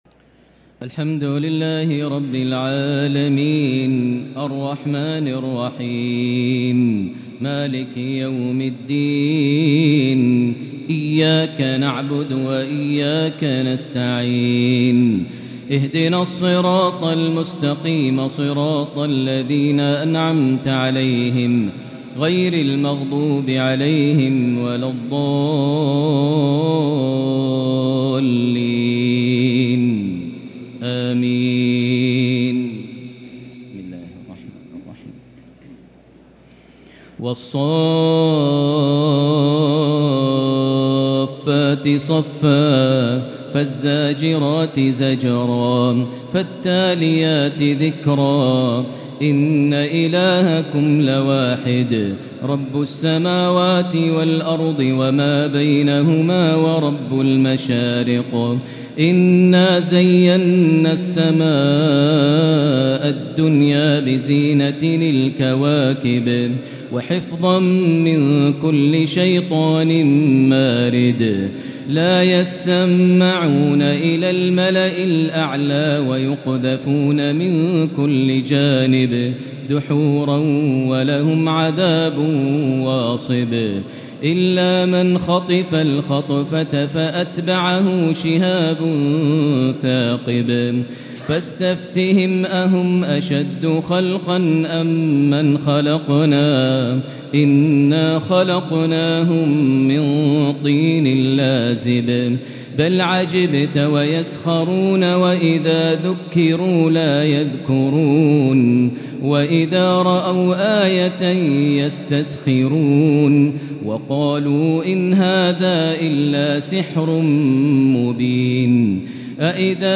تهجد ليلة 25 رمضان 1443هـ| سورة الصافات+ ص+ سورة الزمر(16) |Tahajjud 25st night Ramadan 1443H -Surah As-Saaffat+ Surah Sad + Surah Az-Zumar 1+16 > تراويح الحرم المكي عام 1443 🕋 > التراويح - تلاوات الحرمين